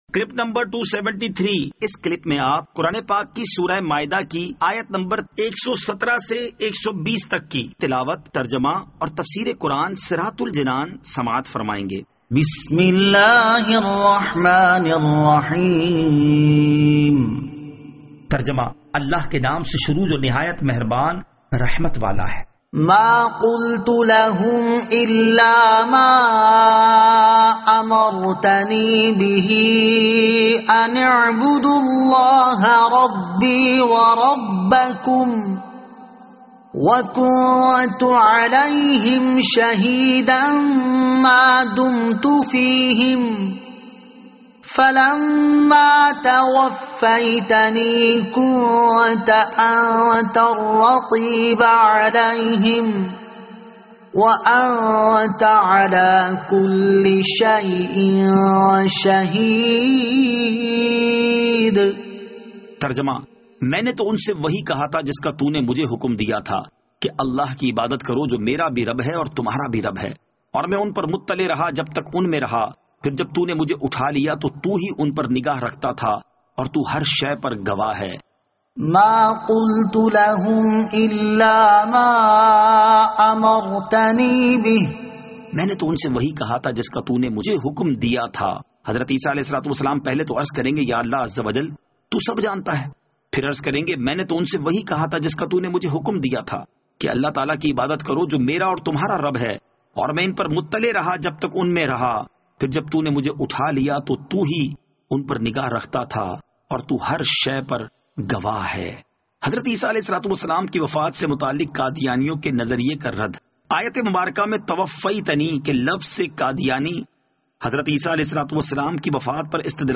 Surah Al-Maidah Ayat 117 To 120 Tilawat , Tarjama , Tafseer